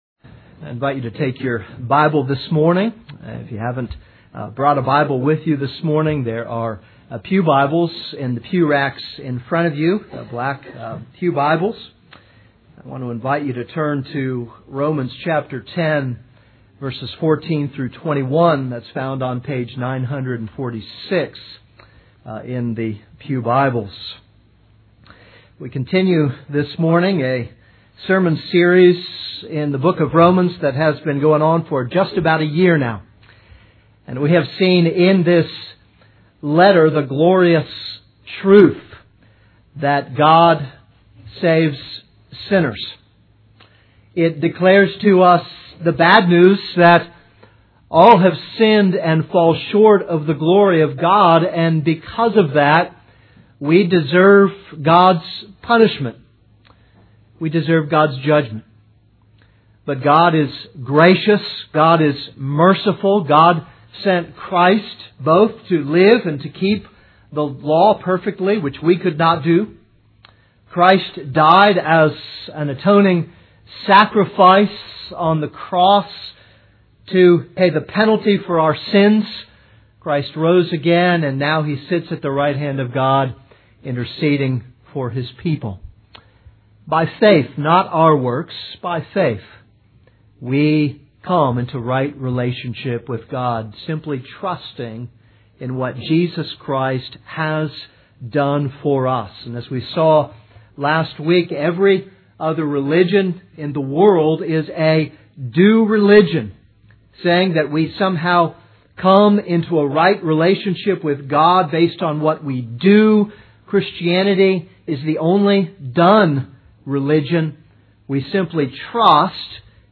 This is a sermon on Romans 10:14-21.